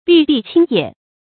闭壁清野 bì bì qīng yiě
闭壁清野发音
成语注音ㄅㄧˋ ㄅㄧˋ ㄑㄧㄥ ㄧㄜˇ